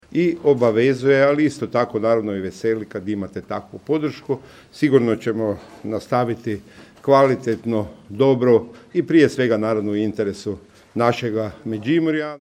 Zahvalio je i zamjeniku Josipu Grivcu koji je nakon njegove ostavke nastavio upravljati Županijom. Nakon ovih izbora ponovno se vraća funkciji zamjenika župana, a nakon primopredaje rezultata poručio je: